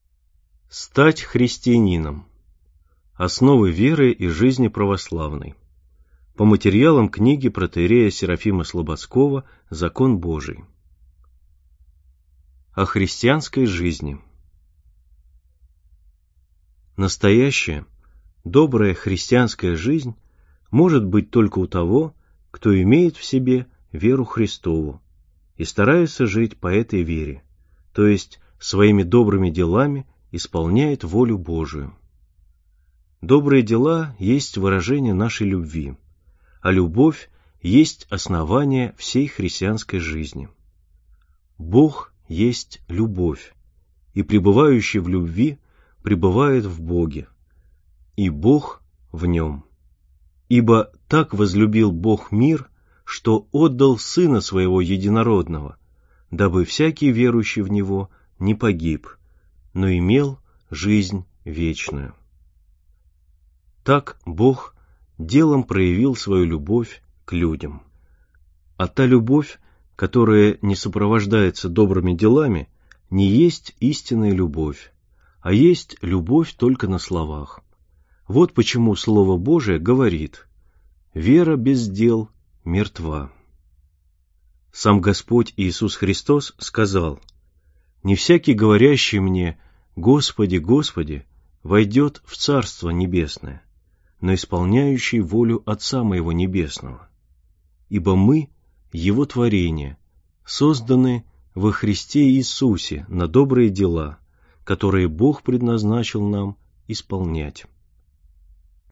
Аудиокнига Стать христианином (основы Веры и жизни православной) | Библиотека аудиокниг
Прослушать и бесплатно скачать фрагмент аудиокниги